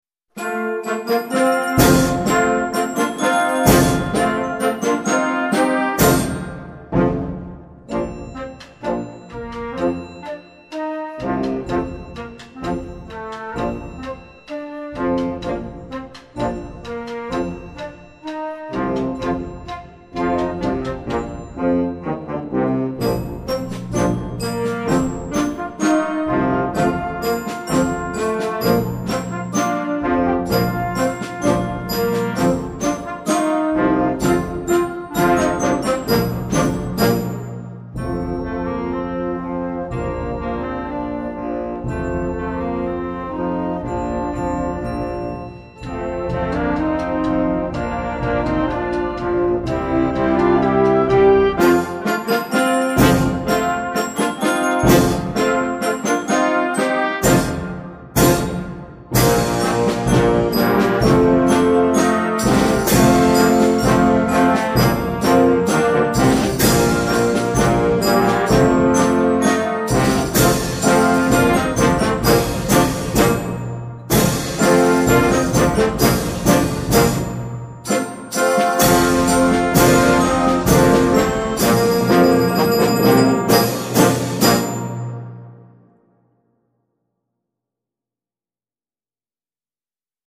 Concert Band
processional piece